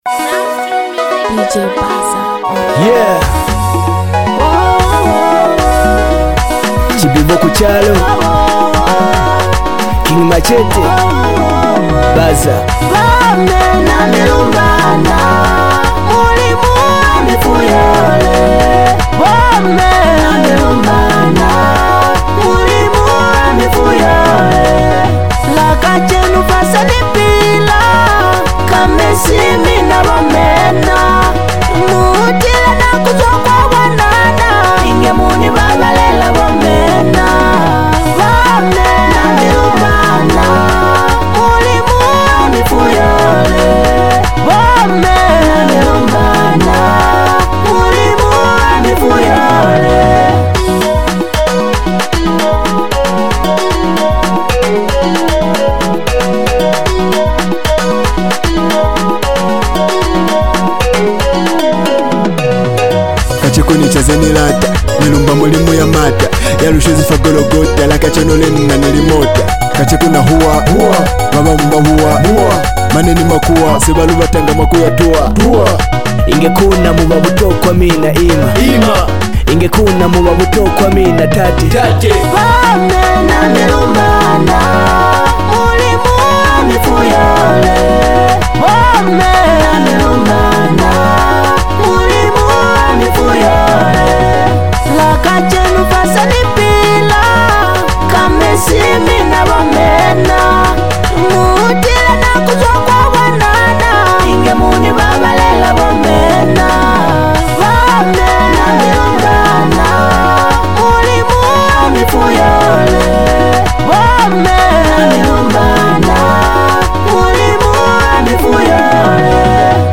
heavyweight Rapper